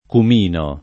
vai all'elenco alfabetico delle voci ingrandisci il carattere 100% rimpicciolisci il carattere stampa invia tramite posta elettronica codividi su Facebook cumino [ kum & no ] o comino (antiq. cimino ) s. m. (bot.)